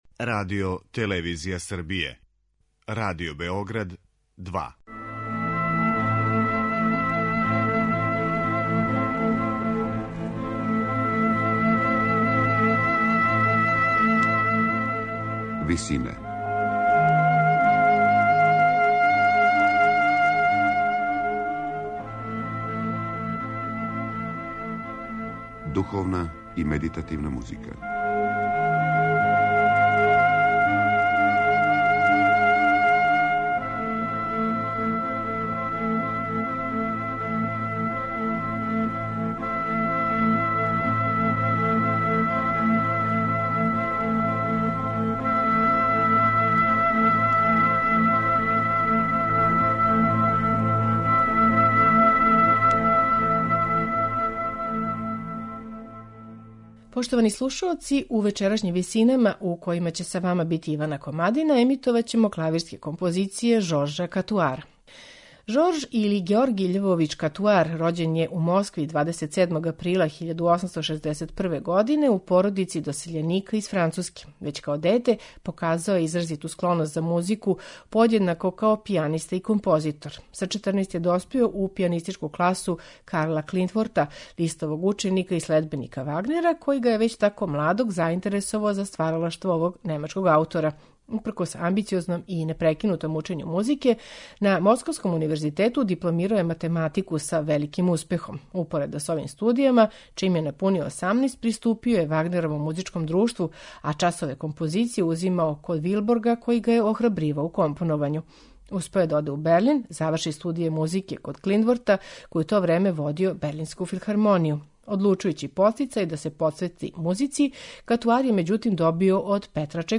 Каутарови клавирски комади и прелудијуми
На известан начин, ово усмерење се одразило и на дух Катуарове клавирске музике, која је, као и лирски комади Чајковског, изразито поетична: мада захтевна за пијанисте, има интимистички карактер и лишена је сваког спољног сјаја. У вечерашњим Висинама слушаћемо четири збирке Катуарових клавирских комада и прелудијума, у интерпретацији пијанисте Марк-Андреа Амлана.